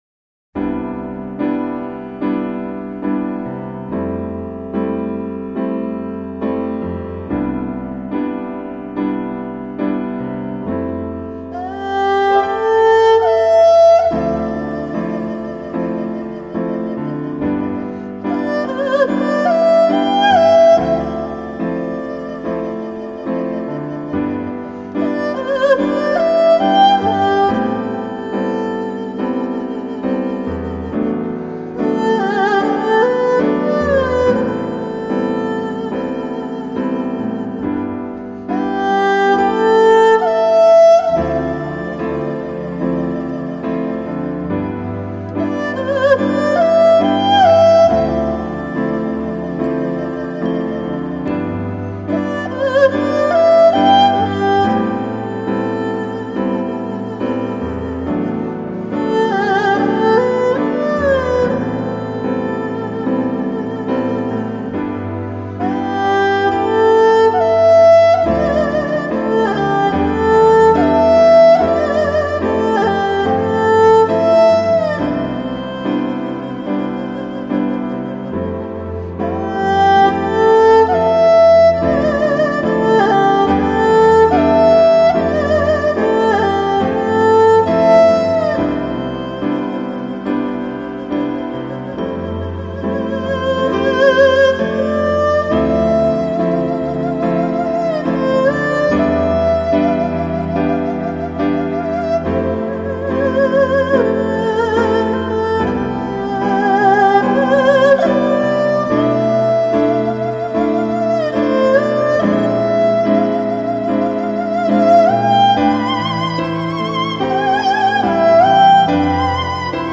东西方的音乐元素是如此完美的融合
舒缓的钢琴主调和东方乐器的润色让我产生了这样的感触。